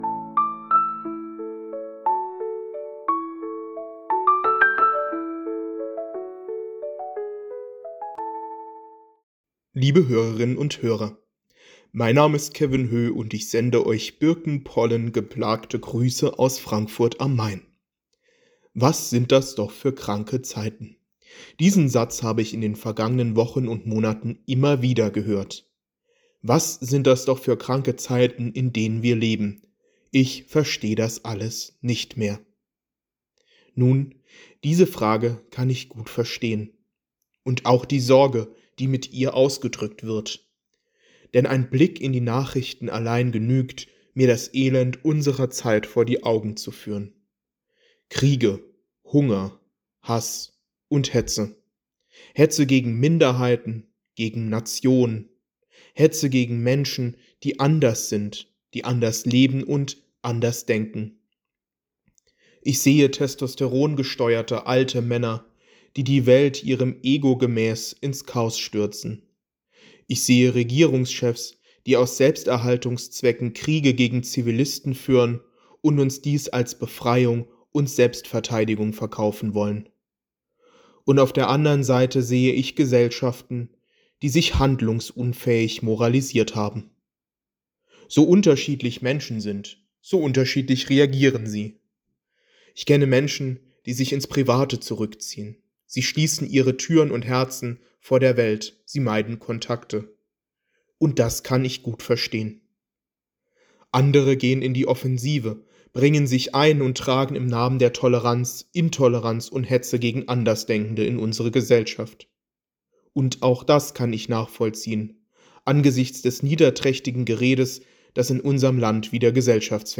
Losungsandacht für Montag, 02.06.2025